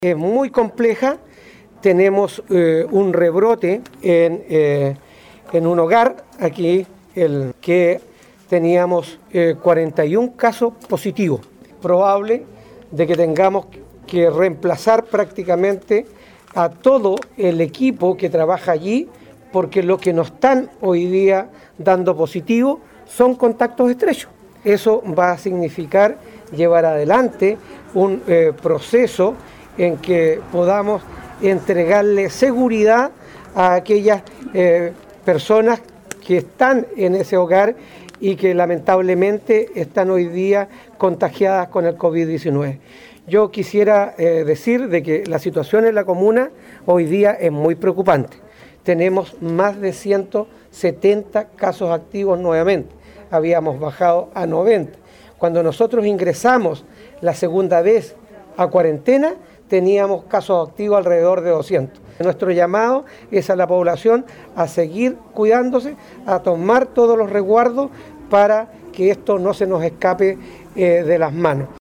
Así lo anunció el alcalde Carlos Gómez al indicar que la situación epidemiológica en la comuna es sumamente preocupante porque se acerca a los números que había cuando se decretó la segunda cuarentena, el 14 de enero pasado.